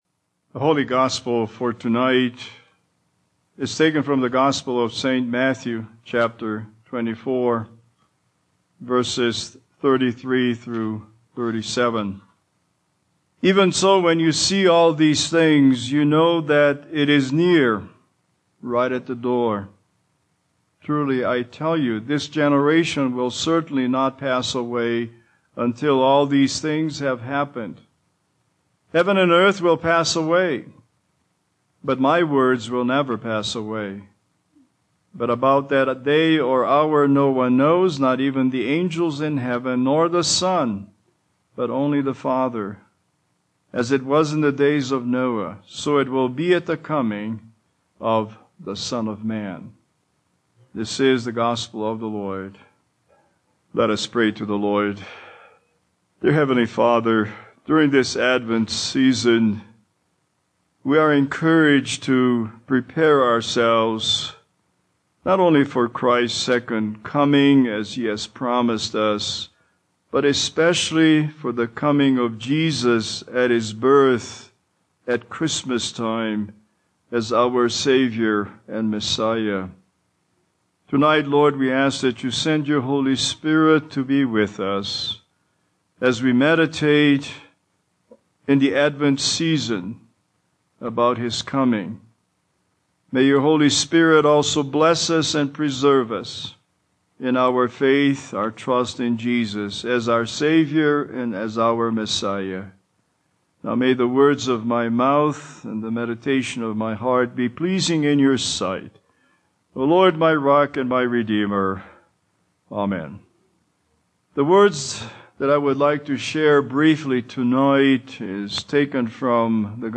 Holiday Sermons Passage
Matthew 24:33-37 Service Type: Advent Litany « SALVATION is nearer now than when we FIRST BELIEVED!